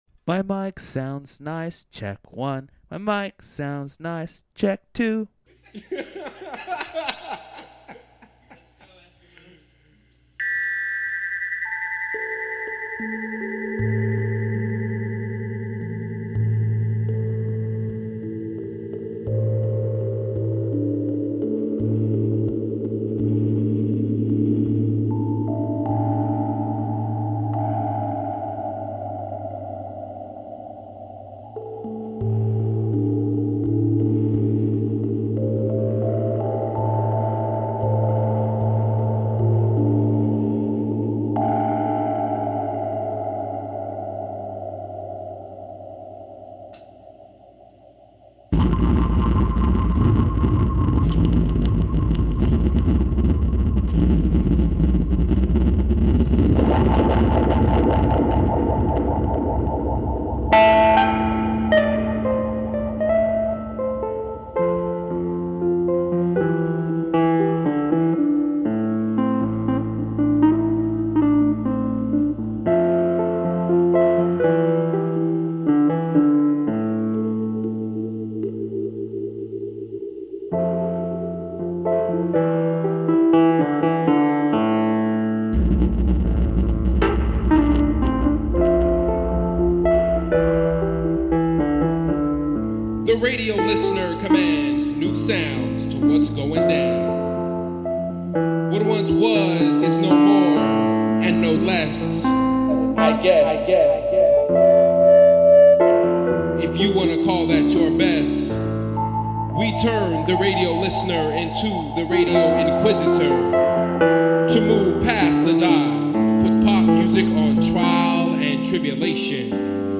Alternative Beats Electronic Post Rock Synth